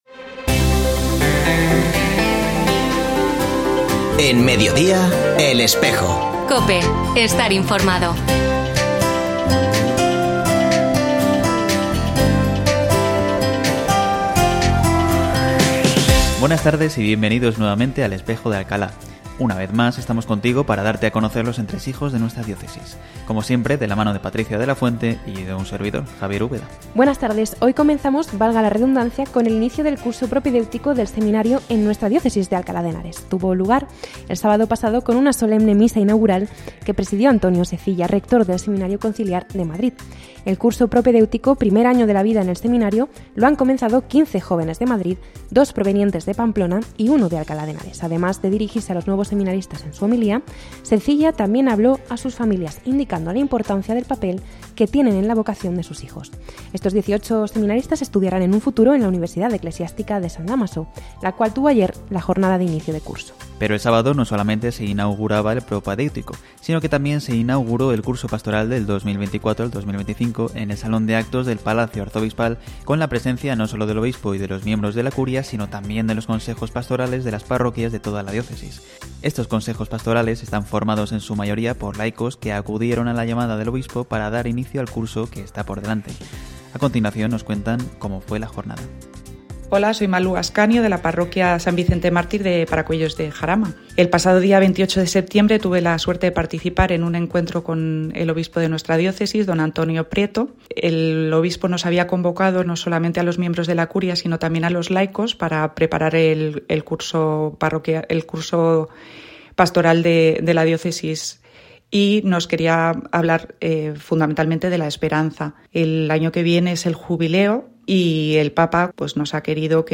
Escucha otras entrevistas de El Espejo de la Diócesis de Alcalá
Una semana más ofrecemos el programa El Espejo de la Diócesis de Alcalá. Se ha vuelto a emitir hoy, último primer viernes de octubre de 2024, en radio COPE. Este espacio de información religiosa de nuestra diócesis puede escucharse en la frecuencia 92.0 FM, todos los viernes de 13.33 a 14 horas.